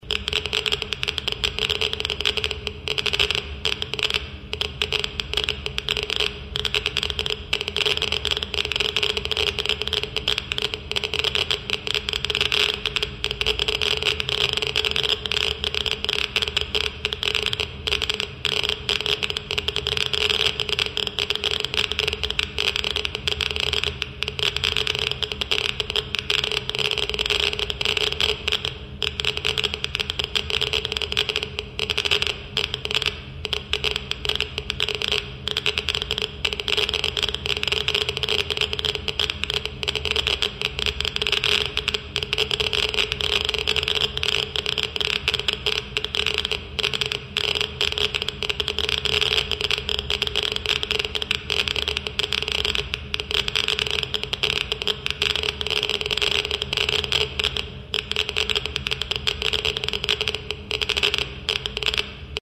Звуки излучения
На этой странице собраны звуки, связанные с излучением: от фонового космического шума до специфических электромагнитных колебаний.